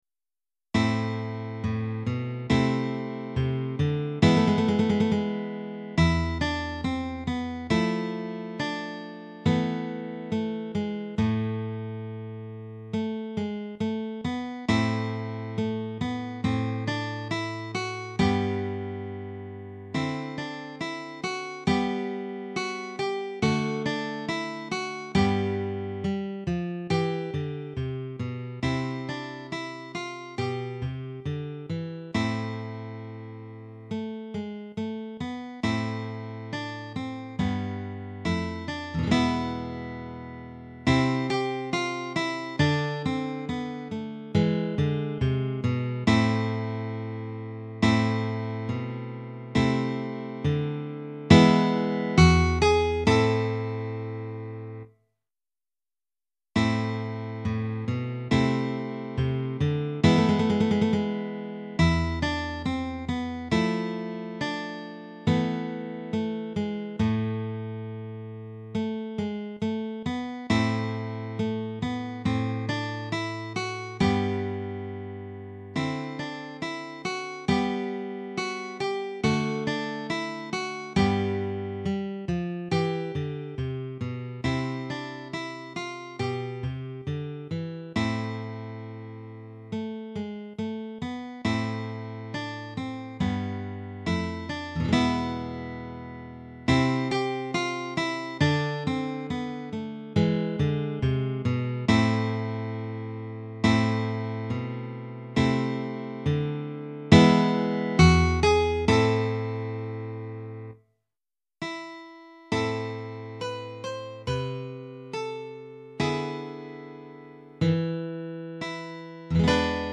Guitare Solo